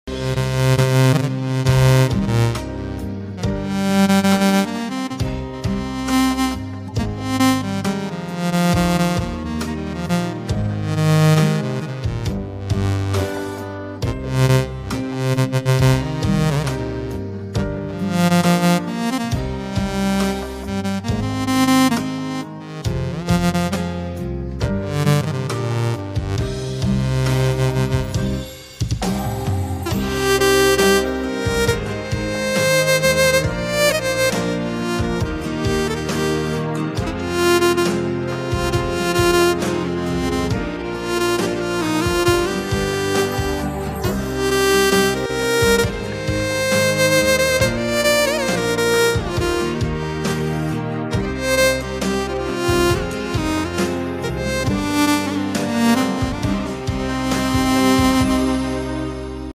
Sunrise Melody MK EWI Sound Sound Effects Free Download